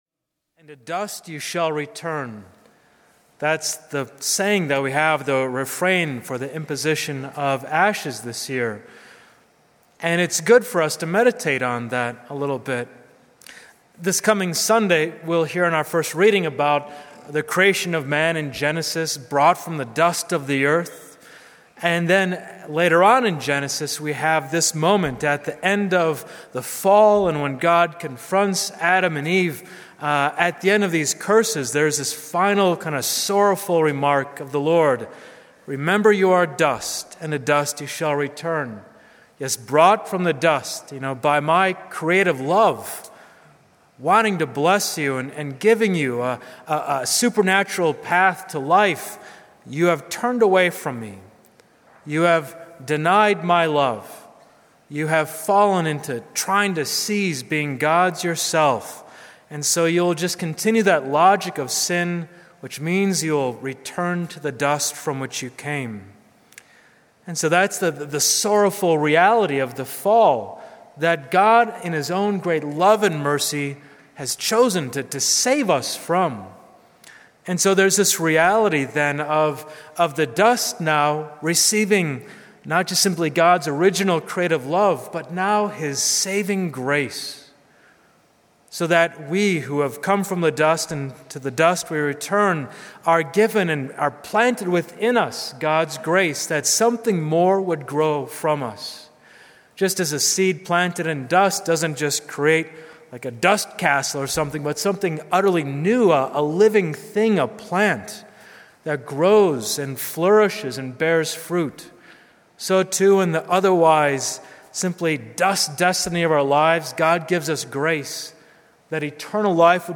ash-wednesday.mp3